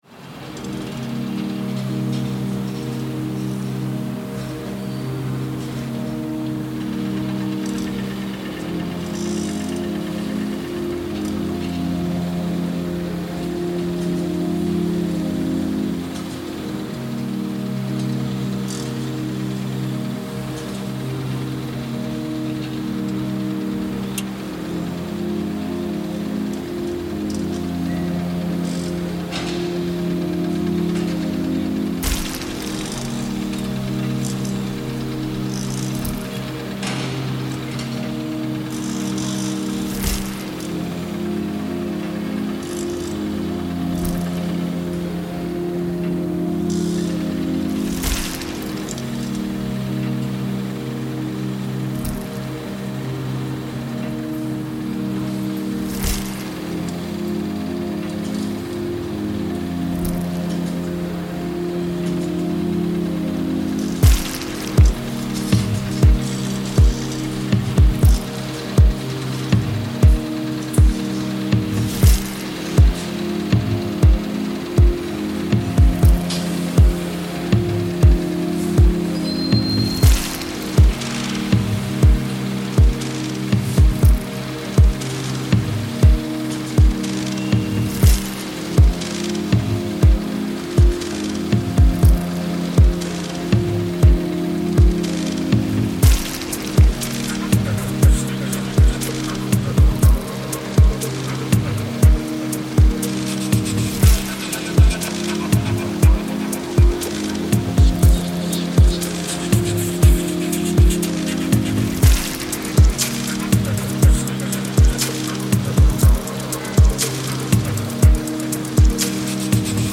Chongqing docks reimagined